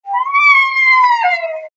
Gemafreie Sounds: Unterwasser
mf_SE-6242-little_whale_3.mp3